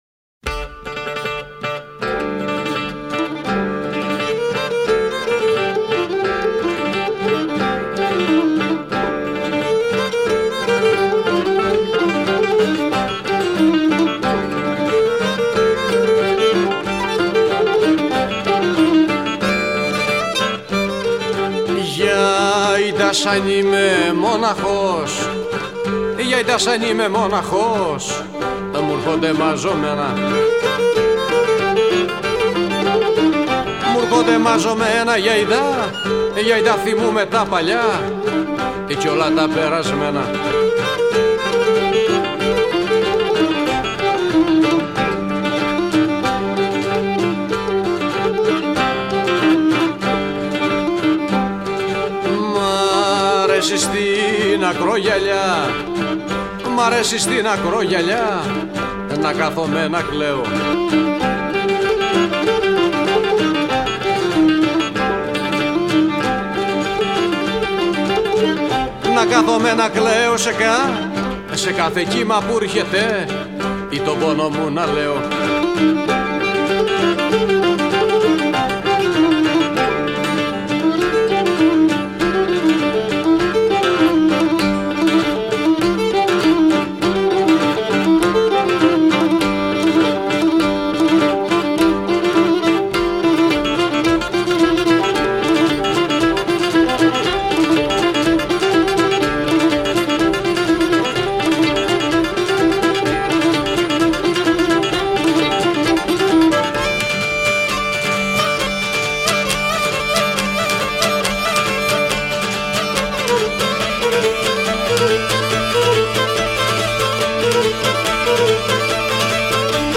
包括各地最独特的民俗乐器、舞蹈及民谣曲风，
在酒歌、舞曲、单簧管、风笛等特色强烈的歌乐演出中，